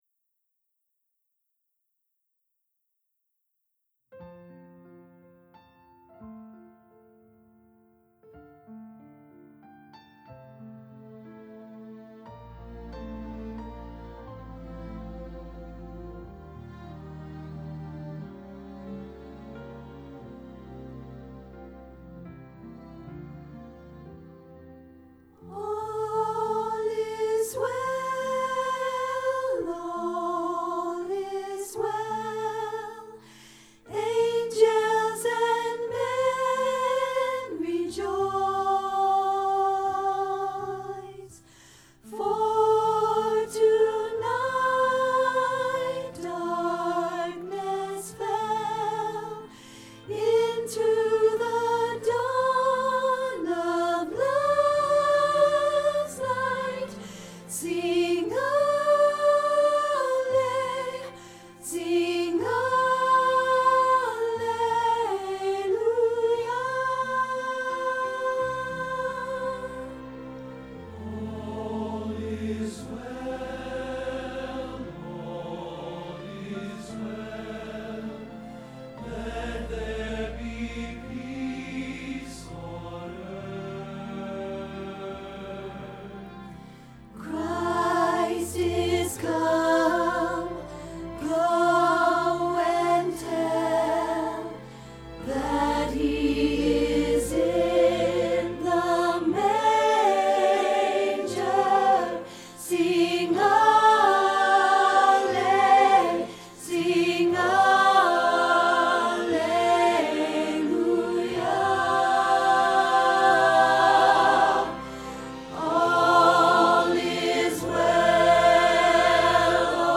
All Is Well- Alto